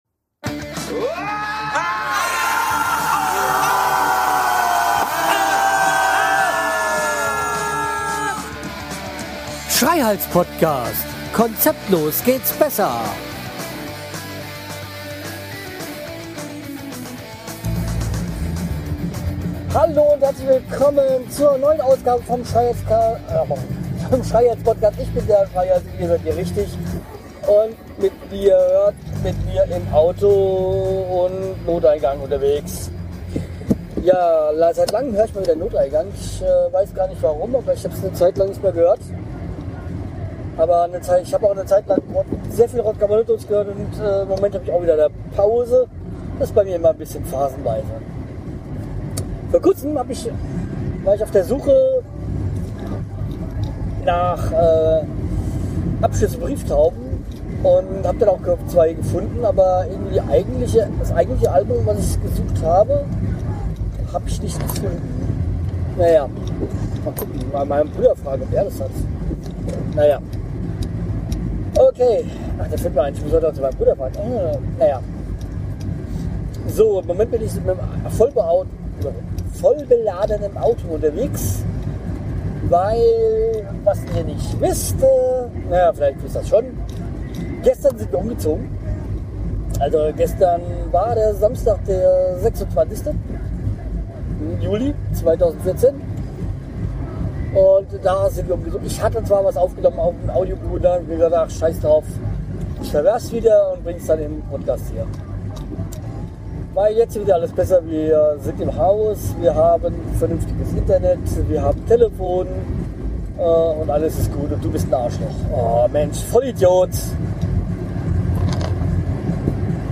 Nach langer Zeit des Abriss und der Renovierung melde ich mich aus dem Auto und berichte vom Umzug, wieso Offenbacher auch Gute Freunde sein können, wieso ich nun Dauergast bei meinem Bruder bin und der Umzug auch eine Geburtstagsparty war.